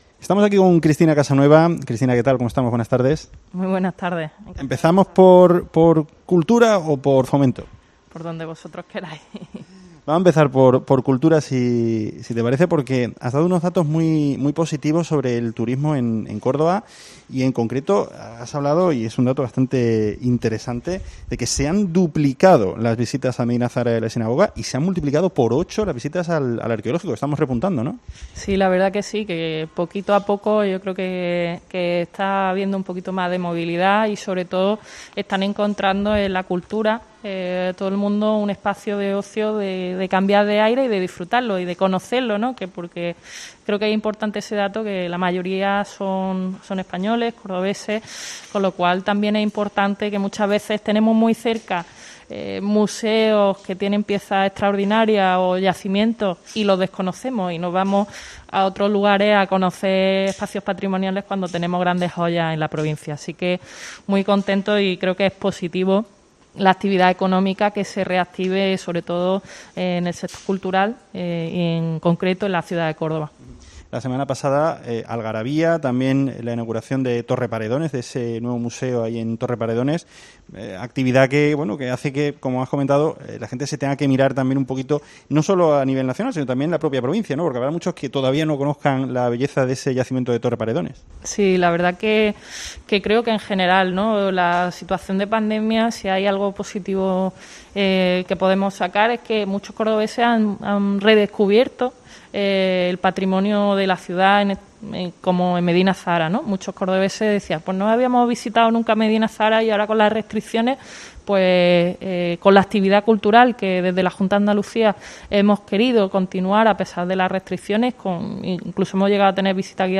La delegada Cristina Casanueva explica en COPE que la mayoría de los turistas que visitan esos monumentos son nacionales